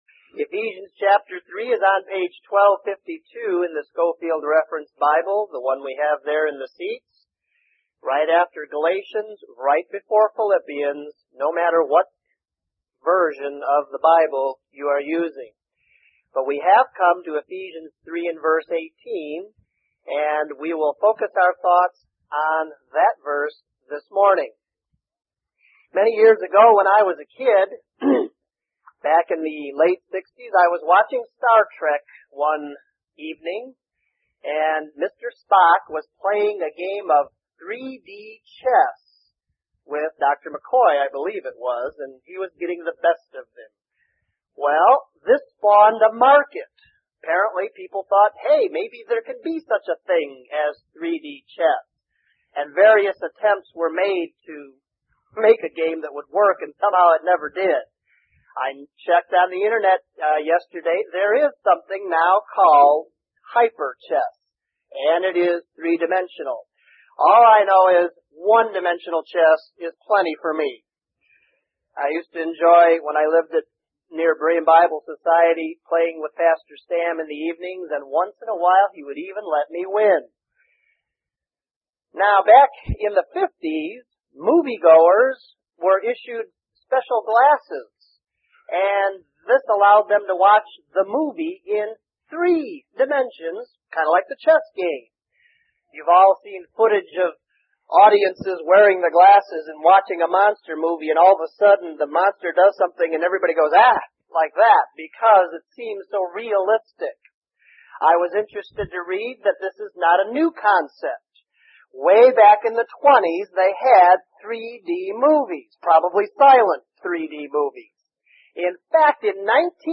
Lesson 38: Ephesians 3:18